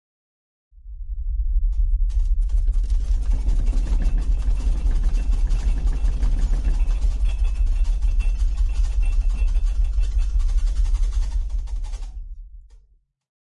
Download Earthquake sound effect for free.
Earthquake